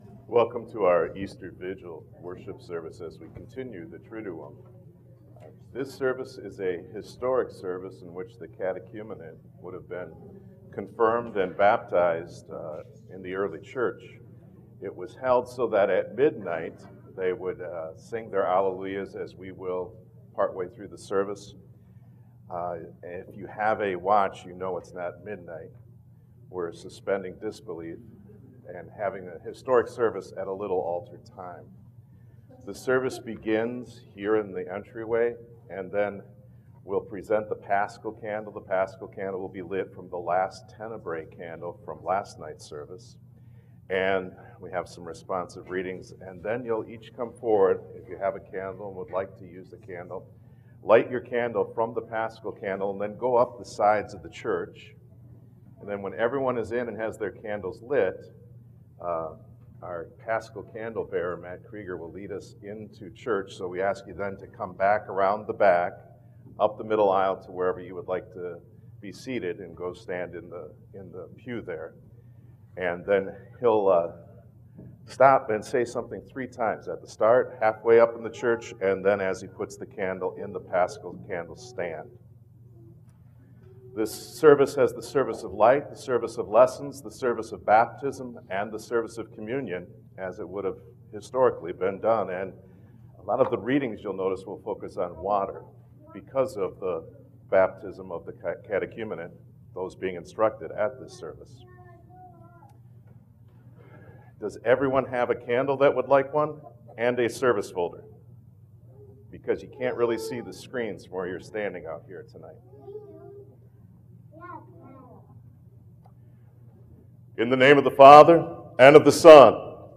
Easter Vigil Worship (Apr. 8, 2023)